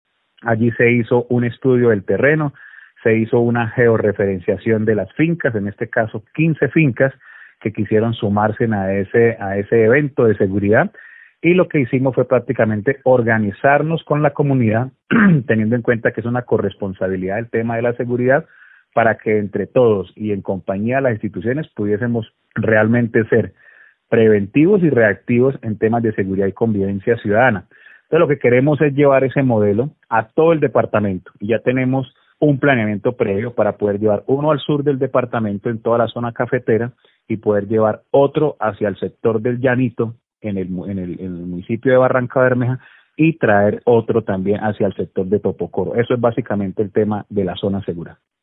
Douglas Arenas, director de Seguridad y Convivencia Ciudadana de Santander